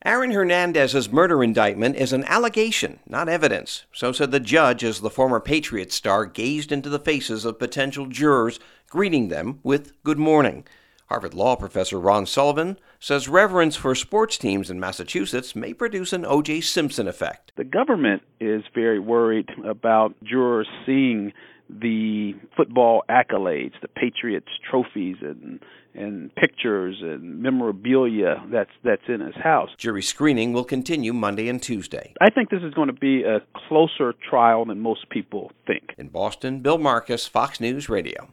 FROM BOSTON.